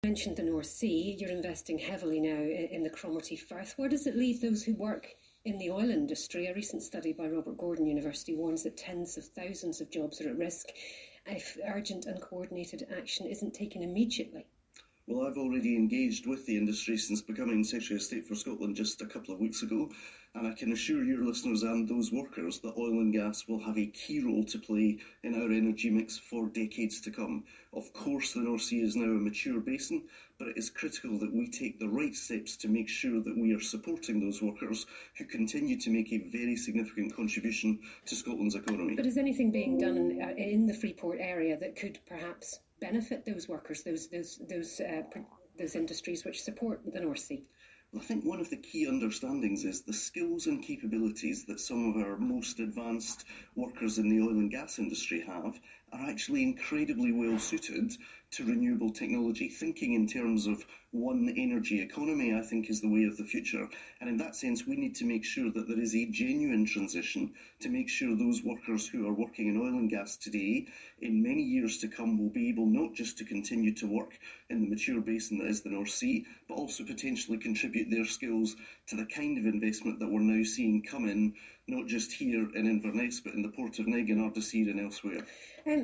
Radio Scotland – 26th Sept 2025 – RGU Report discussed in Interview with Douglas Alexander Secretary of State for Scotland